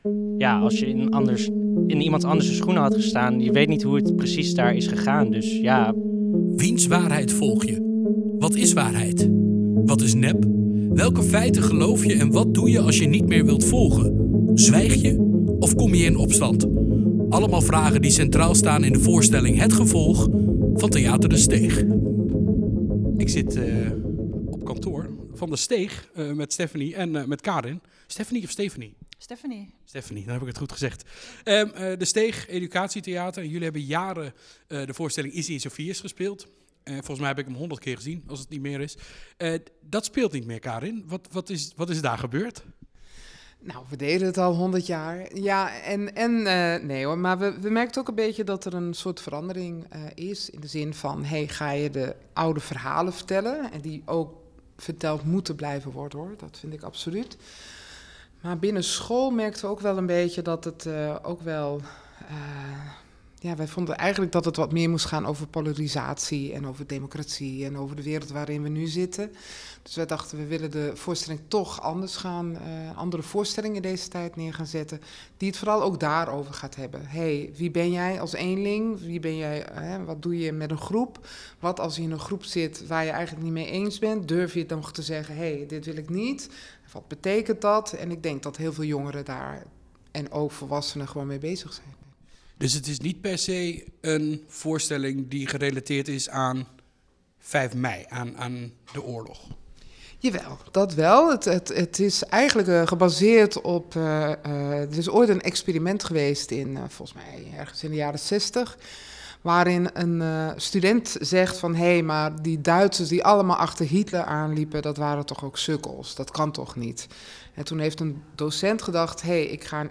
Interview De Steeg
Elke dag was er een ander interview te beluisteren in de OOG ochtendshow, een nieuwsprogramma op OOG Radio, elke werkdag van 7.00 tot 9.00 uur. 80 jaar bevrijding 80 jaar vrijheid fascisme Oog Ochtendshow theater de steeg